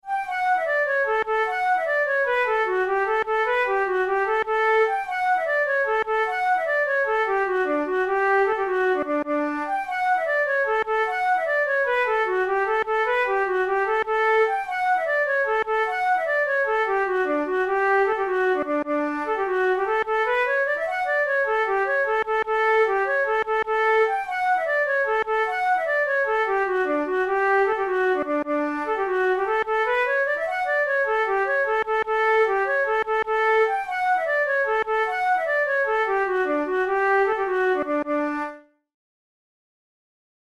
InstrumentationFlute solo
KeyD major
Time signature6/8
Tempo100 BPM
Jigs, Traditional/Folk
Traditional Irish jig
This lively jig appears to be unique to Francis O'Neill's collection The Dance Music Of Ireland, published in Chicago in 1907.